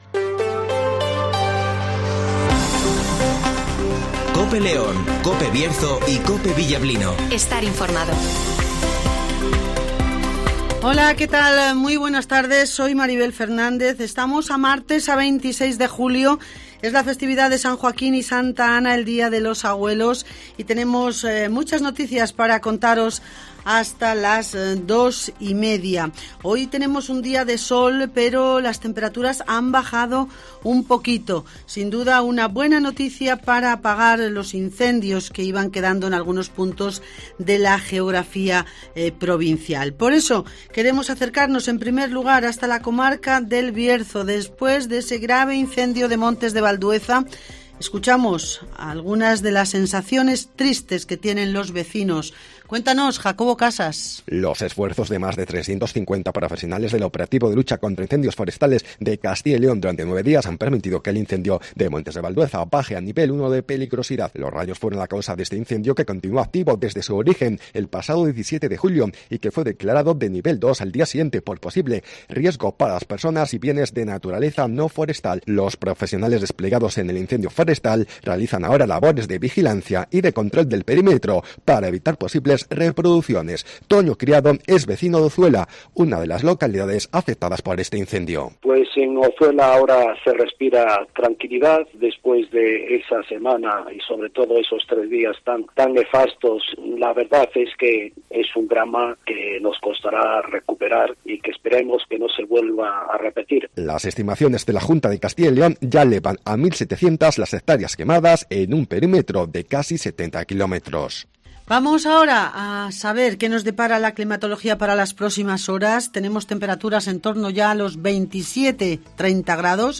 - Santiago Dorado ( Alcalde de La Robla )